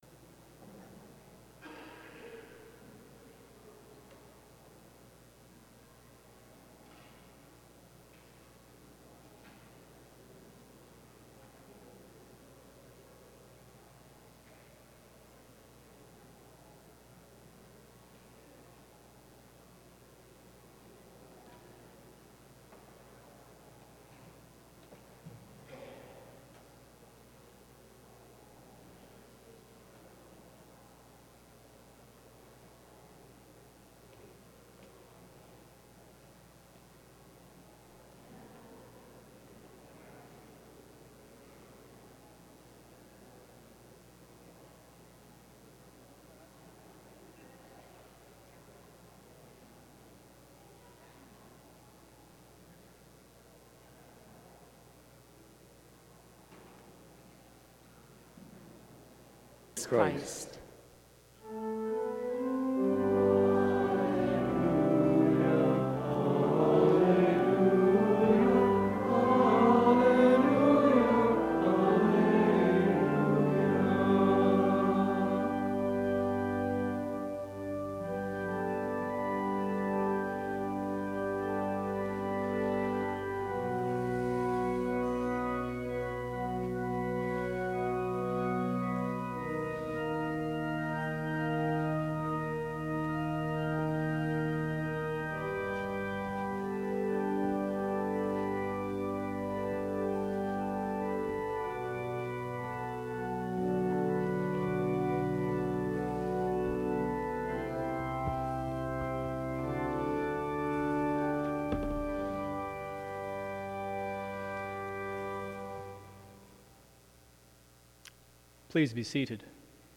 Sermon: 9.15 a.m. service